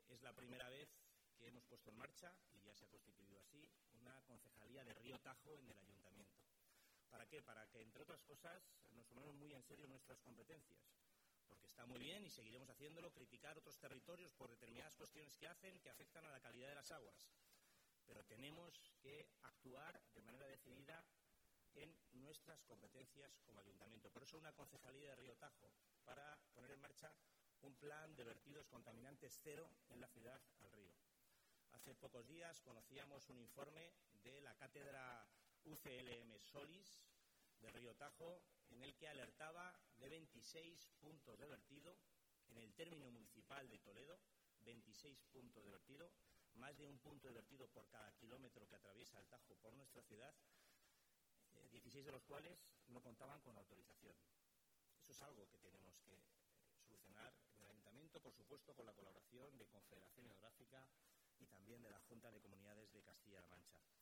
Cortes de Voz
el-alcalde-de-toledo-carlos-velazquez-participa-en-el-iv-congreso-iberico-de-restauracion-fluvial1.mp3